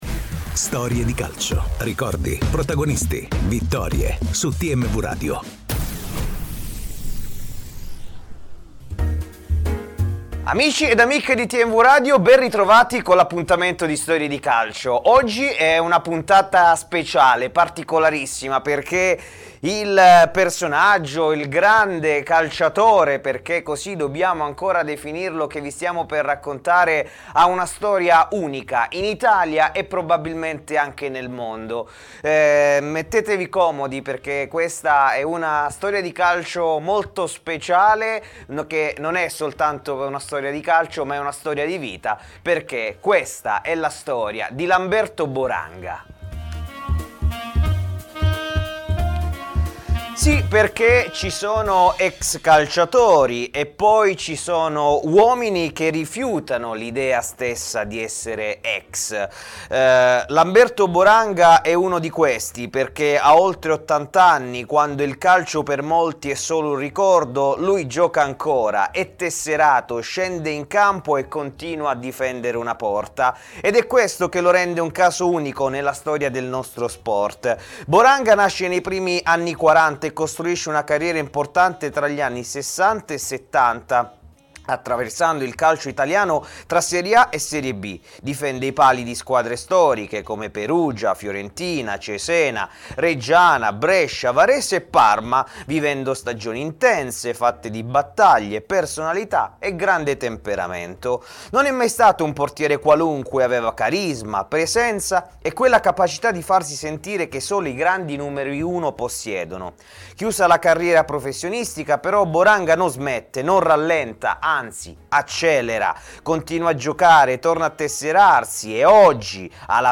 che si è confessato ai microfoni di TMW Radio durante Storie Di Calcio.